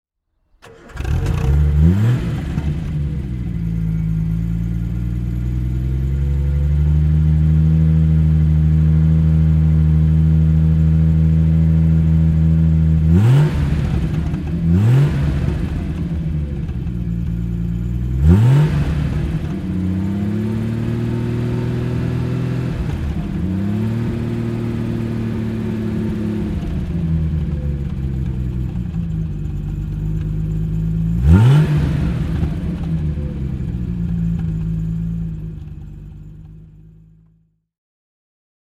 Triumph GT 6 (1971) - Starten und Leerlauf
Triumph_GT6_1971.mp3